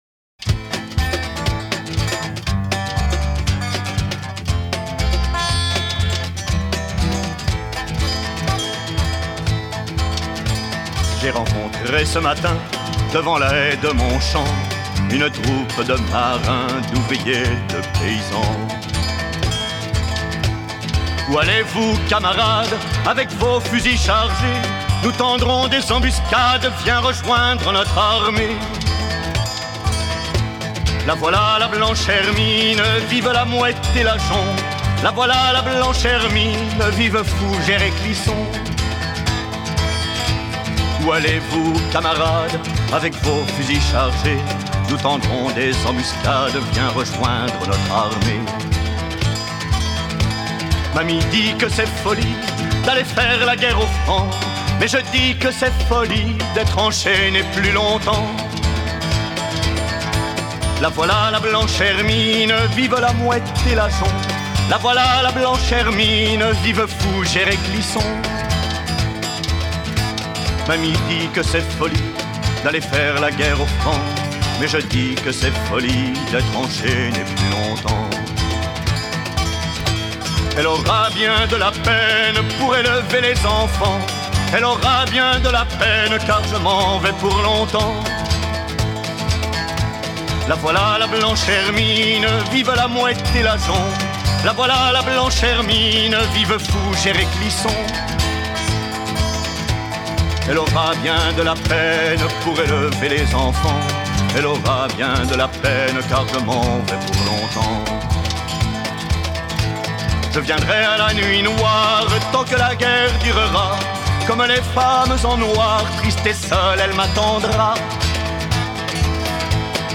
une protest song qui émerveille